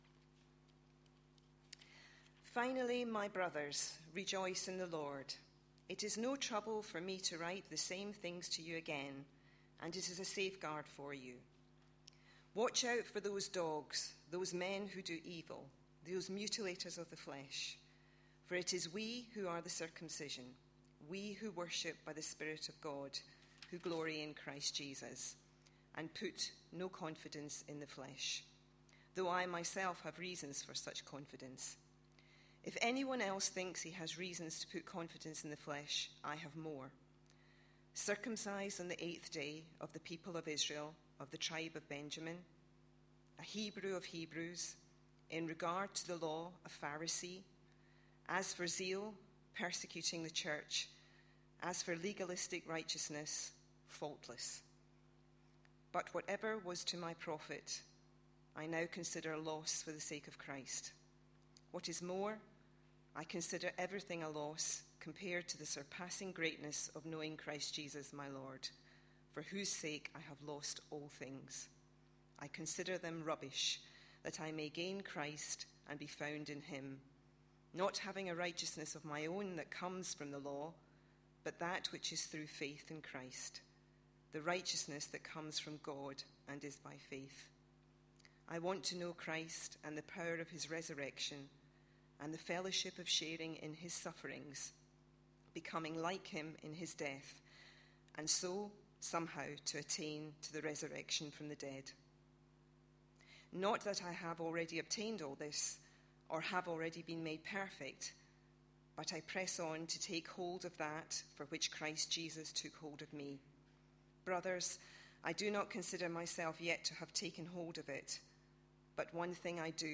A sermon preached on 19th August, 2012, as part of our Philippians series.